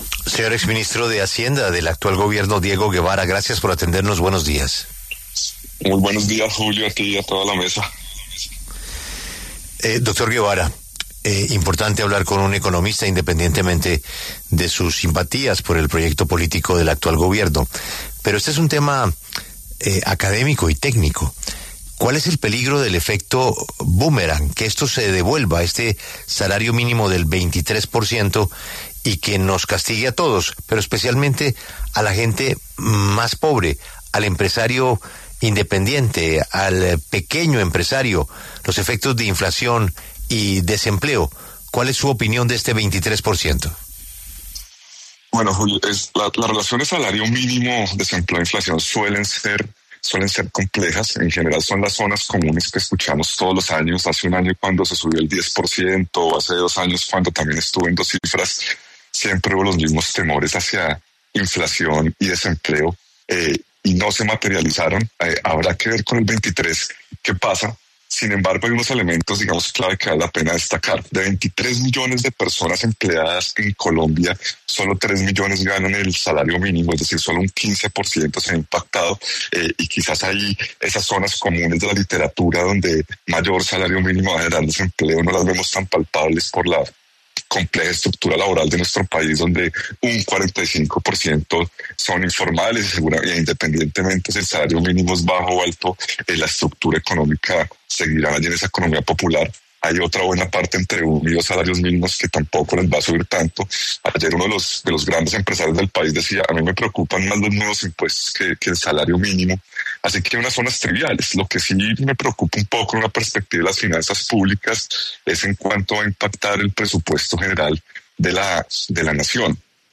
Diego Guevara, exministro de Hacienda, pasó por los micrófonos de La W y habló sobre el incremento del 23,7% del salario mínimo 2026, anunciado por el presidente Petro.